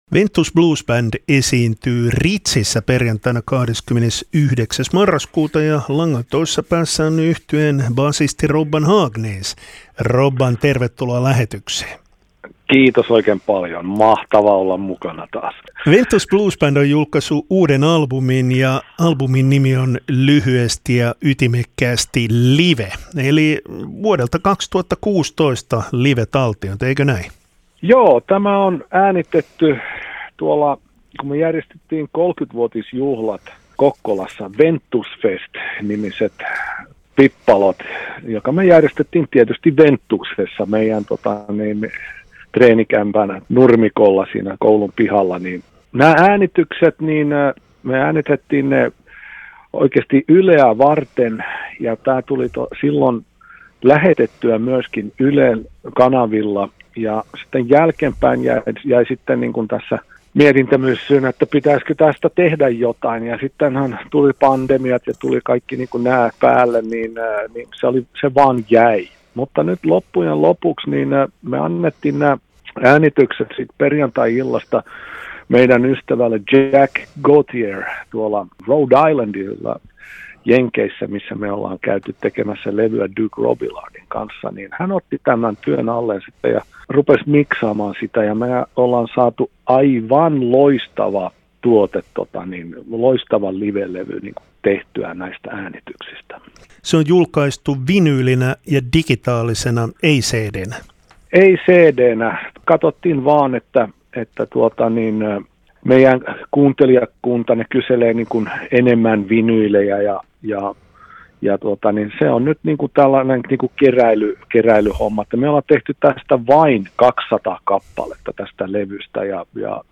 Radio Vaasa